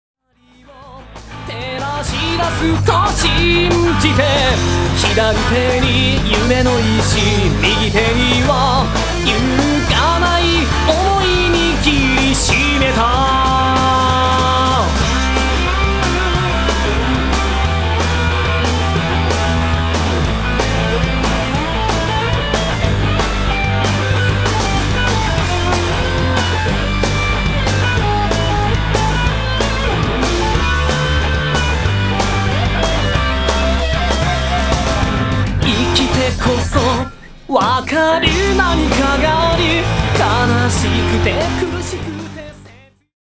例の、一発録り！！です。。